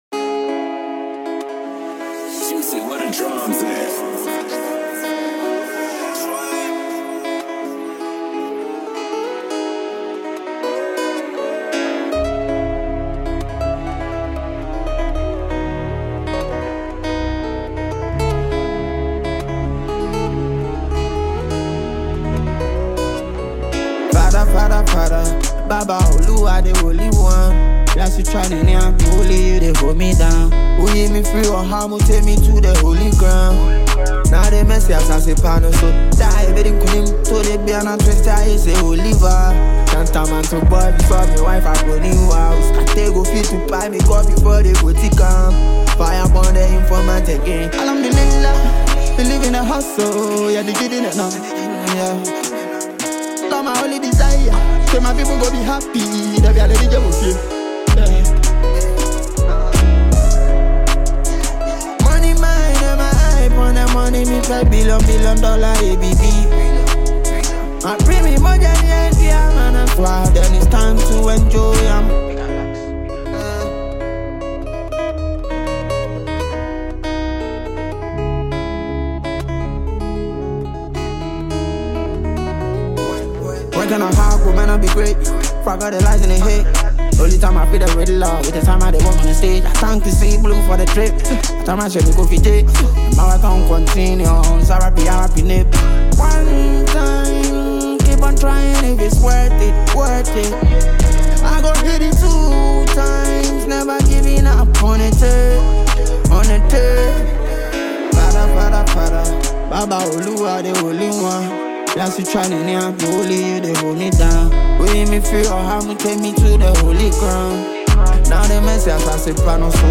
a Ghanaian trapper
is a solo jam